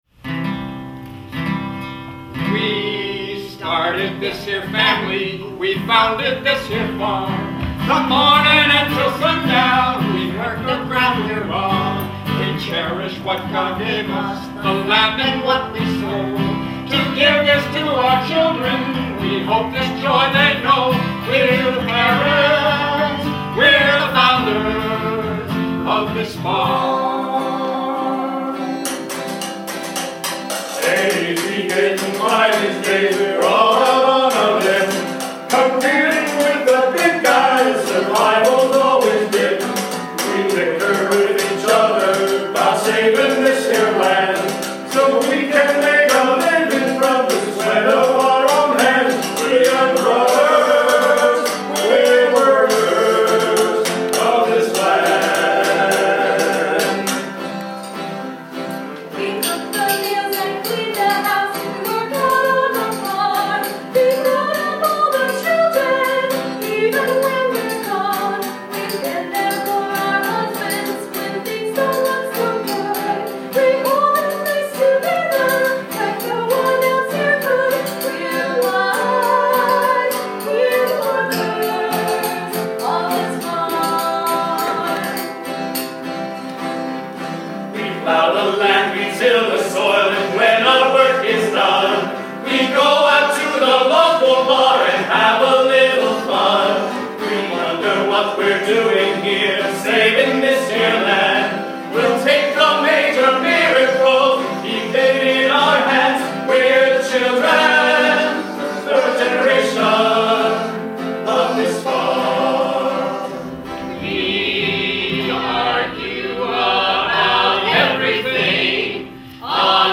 Full song from 2008 reading (